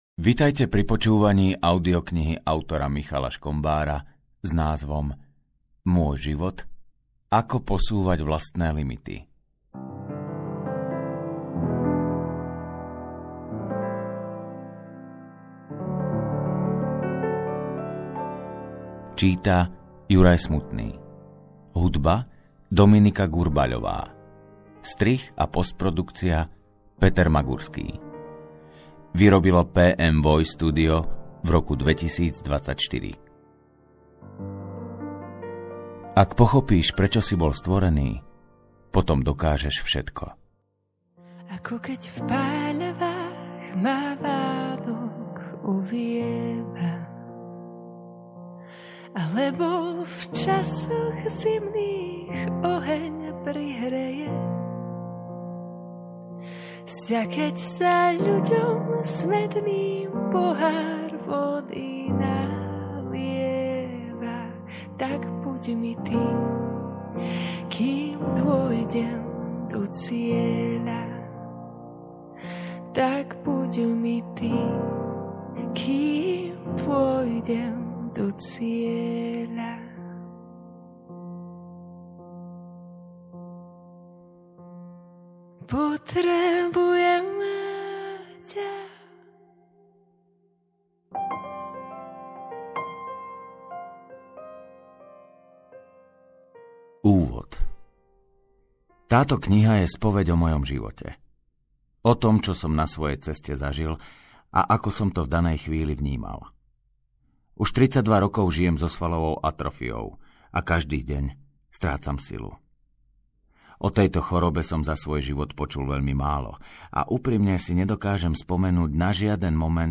Môj život: Ako posúvať vlastné limity audiokniha
Ukázka z knihy